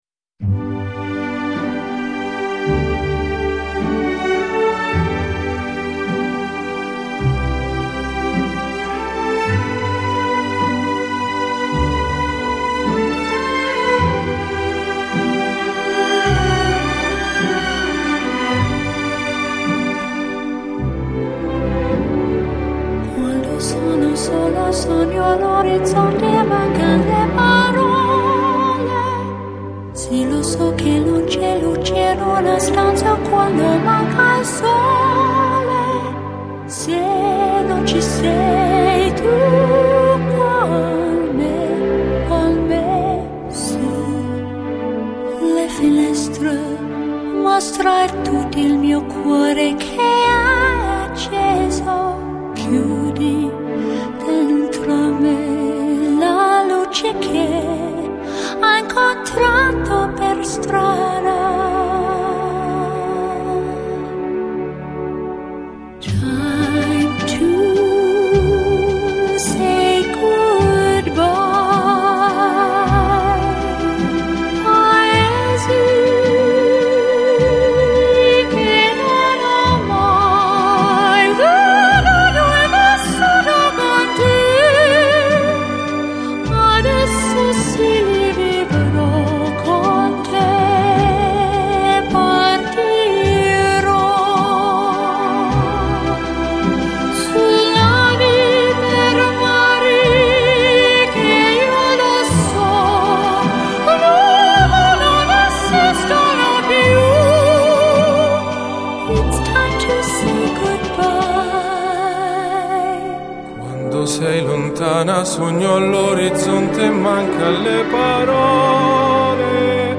Главная » Популярная музыка